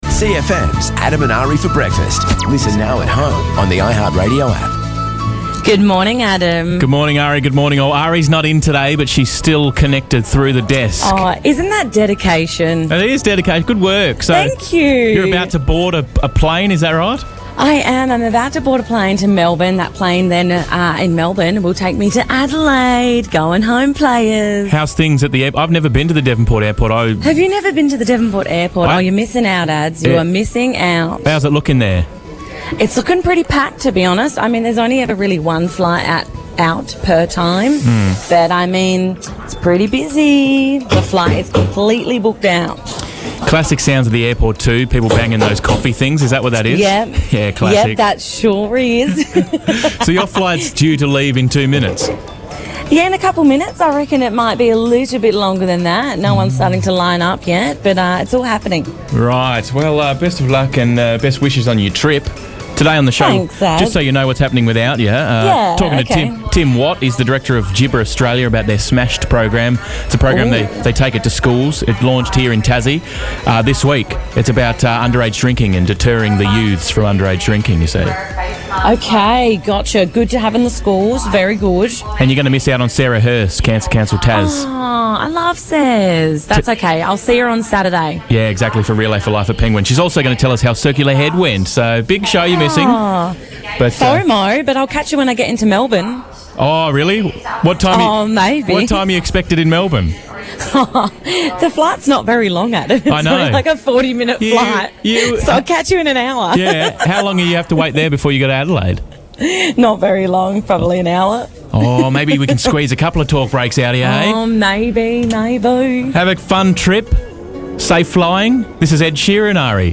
live from airport.